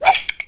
wrench_lower.wav